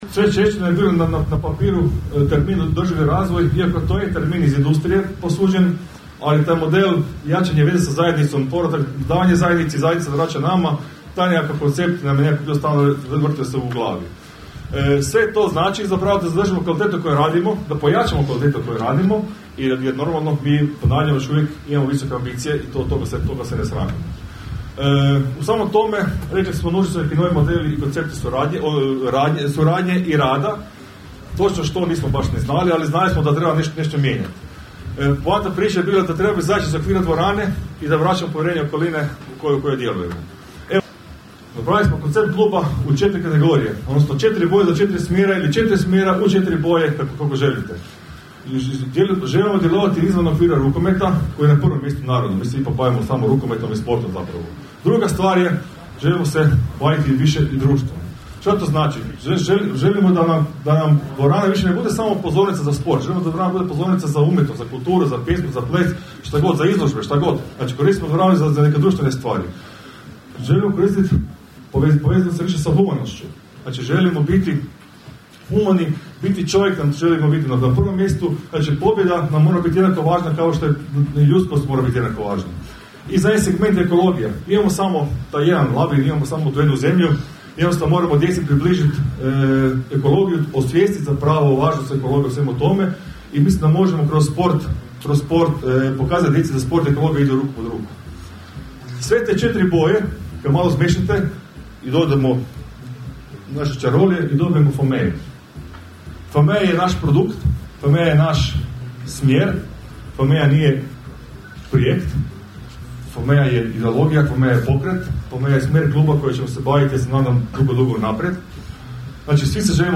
Pod nazivom "Season Kick-off 2025./26.", Rukometni klub Rudar jučer je u prelijepom ambijentu Glamping campa Floria predstavio viziju, ciljeve, momčad, stručni stožer i novi Izvršni odbor za nadolazeću sezonu.